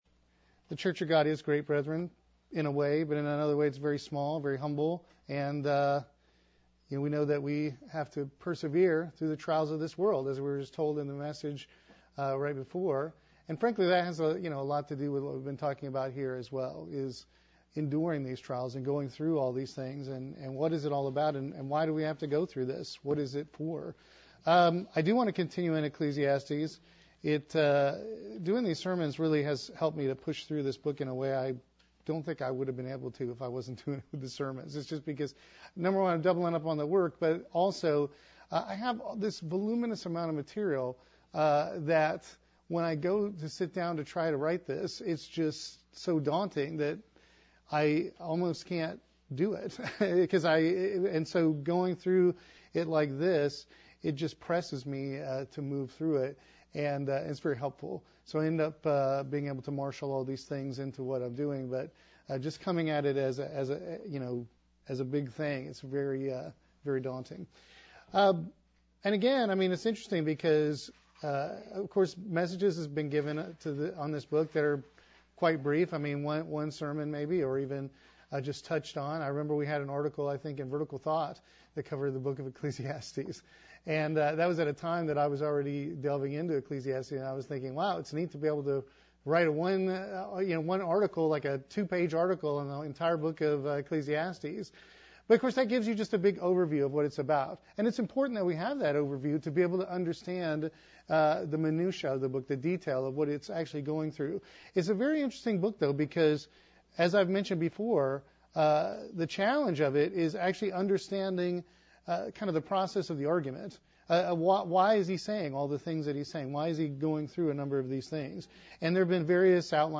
This sermon starts with Ecclesiastes 7:15 and continues to the end of chapter 7.